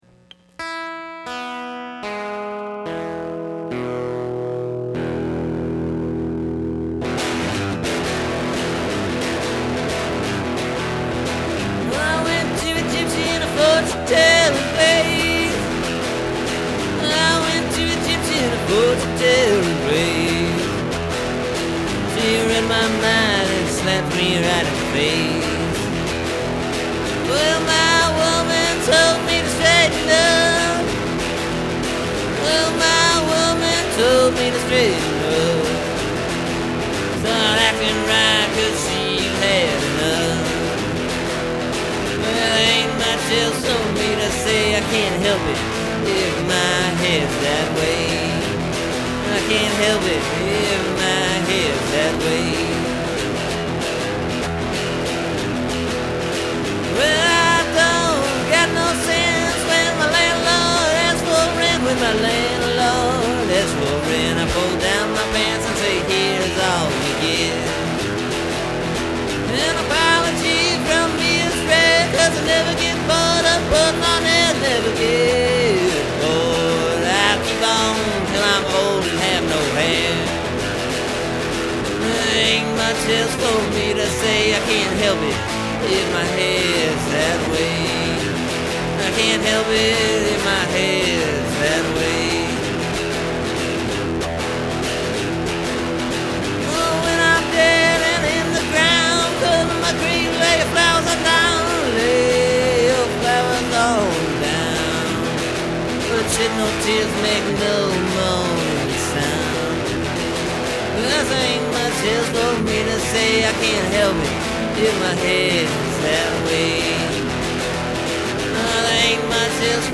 Recorded last night.
You’ll notice I used an electric guitar on this one.
The song’s basically the same structure, other than singing it a little different and I broke out the last two lines of the original first verse and used them as a chorus throughout instead of a repeating line.
And, for those of you who’re interested…I plugged the guitar right into my Tascam USB Interface. Used no effects or anything like that in Garageband. Just turned up the volume and got some good old unfiltered digital feedback. I’ve got a 1955 Fender Telecaster.